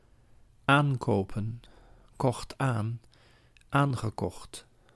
Ääntäminen
IPA: [ˈan.ko.pə(n)]